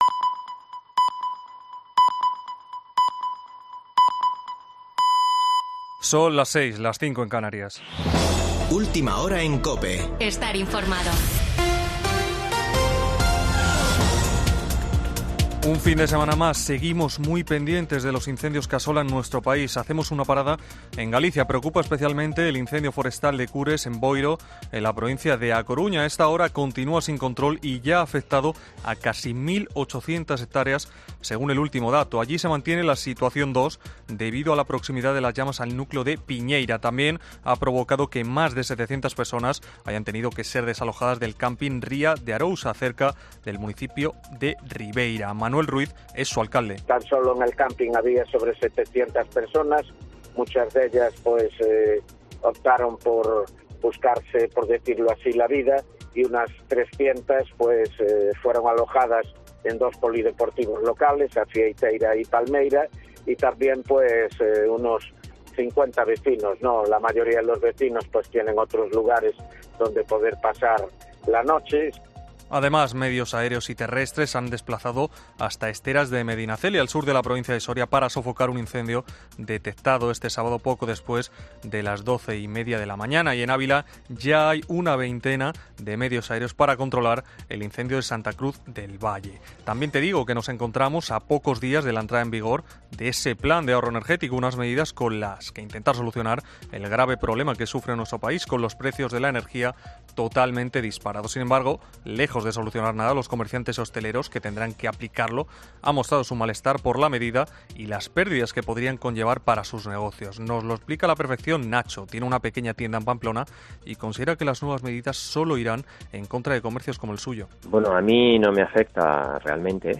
Boletín de noticias de COPE del 6 de agosto de 2022 a las 18.00 horas